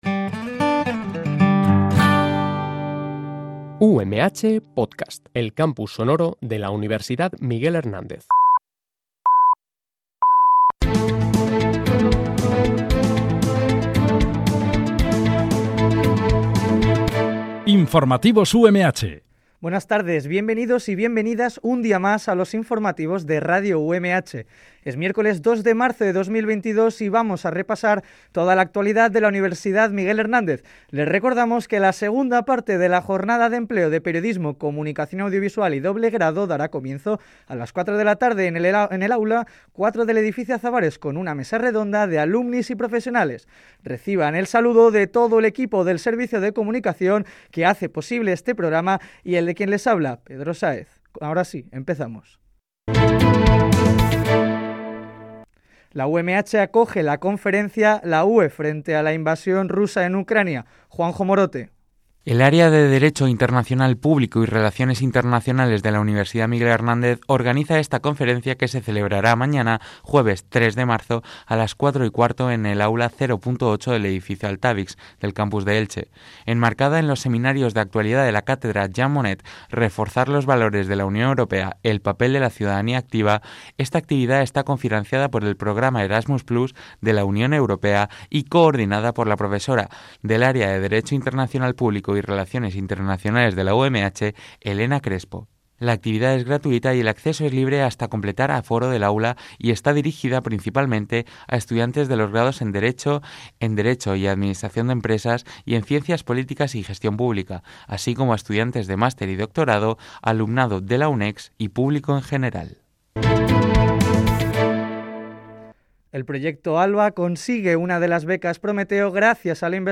Este programa de noticias se emite de lunes a viernes, de 13.00 a 13.10 h